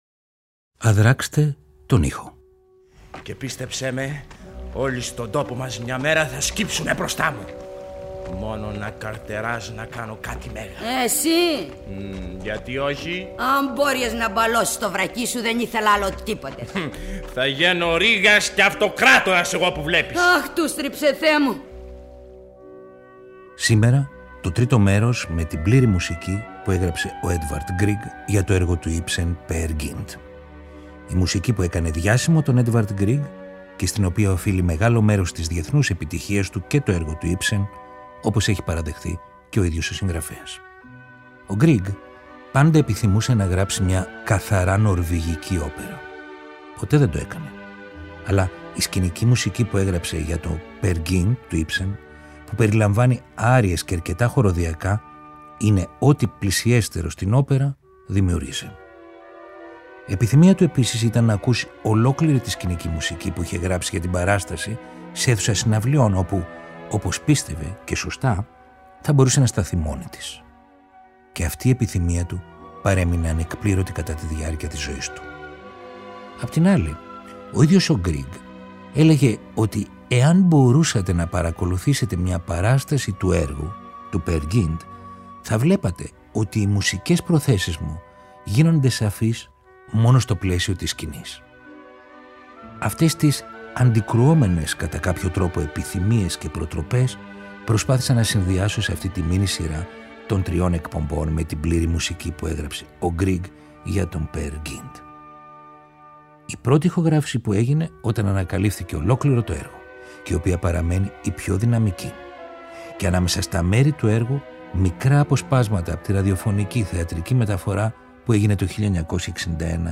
Ένας αντιήρωας που λοιδορείται και βρίσκει διέξοδο στη φαντασία και το όνειρο. Ολόκληρη η εκπληκτική σκηνική μουσική του Έντβαρντ Γκριγκ για το αριστουργηματικό έργο του Ίψεν Peer Gynt, μαζί με αποσπάσματα από την ηχογράφηση του 1960 της ραδιοφωνικής μεταφοράς, με πρωταγωνιστή τον Δημήτρη Παπαμιχαήλ.